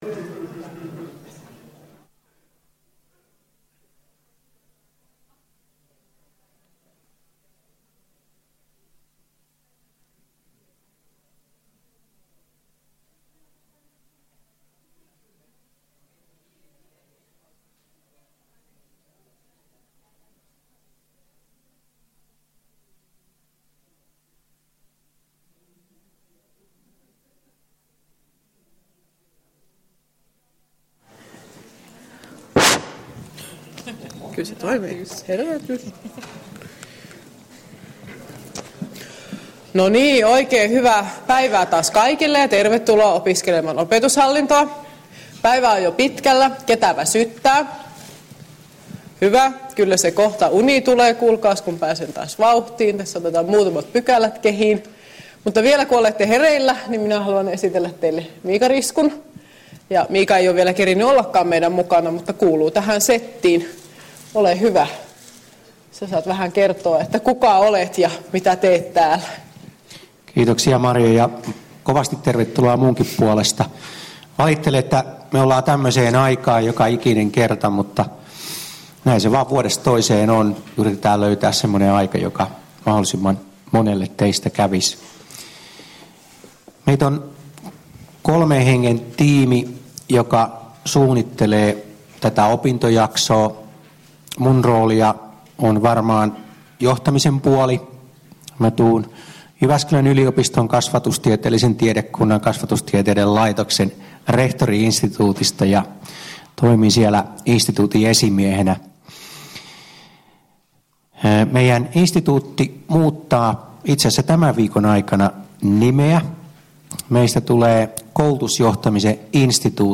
Luento 9.2.2015 — Moniviestin